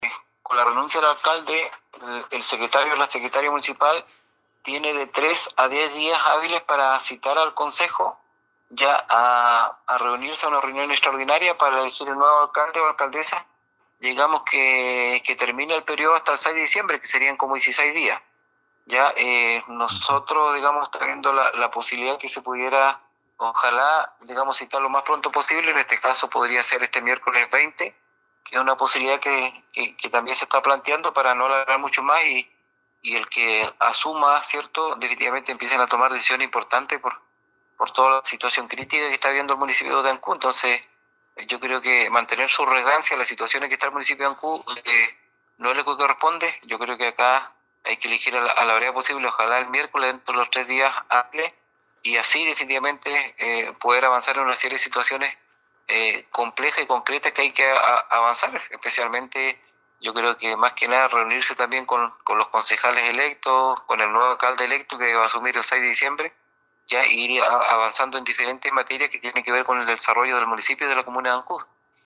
El concejal Alex Muñoz, dijo que era imperativo que se cumpla con ese trámite dado el acumulado de problemas que aquejan a Ancud, y que deben corregirse durante estos días, así como un urgente reunión con quien asumirá en funciones dentro de dos semanas y sus concejales.
18-CONCEJAL-ALEX-MUNOZ-.mp3